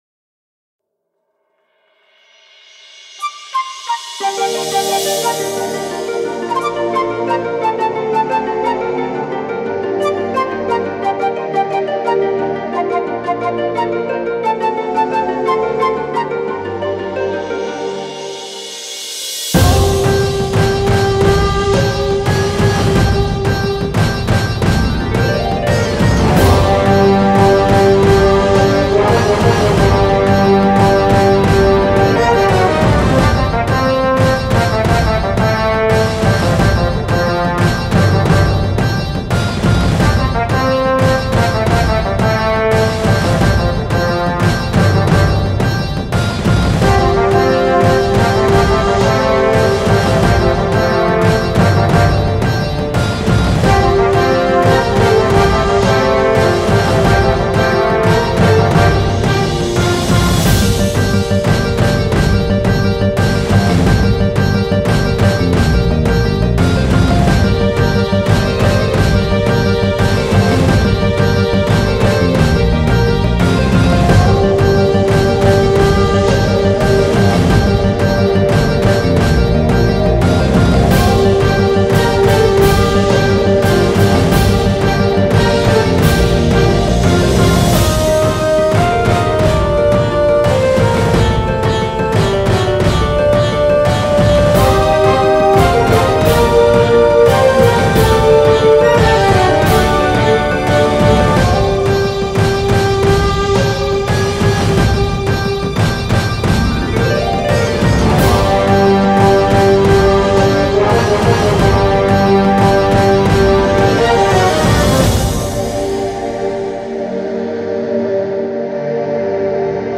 February 6, 2025 Publisher 01 Gospel 0